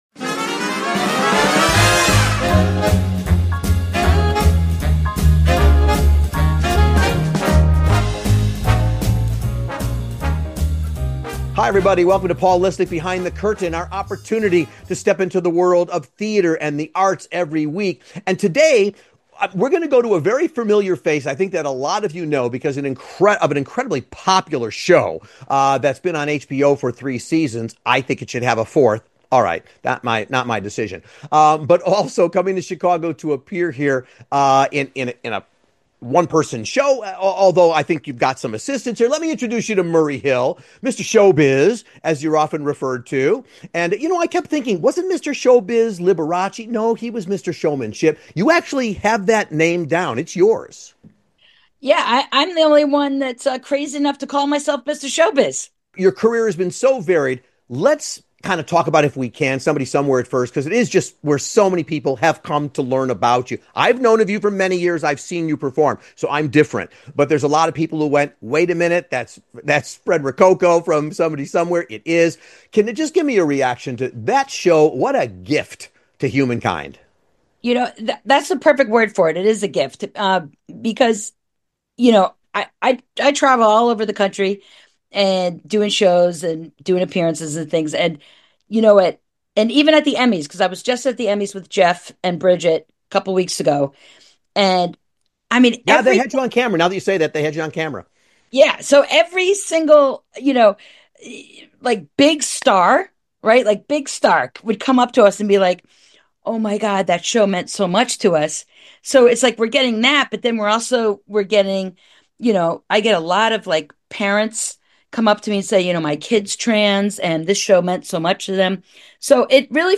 Appearing on TV, in films (including the upcoming ”Man with The Bag” with Arnold Schwarzenegger) and so much more, tune in to this fun interview for all things Showbiz!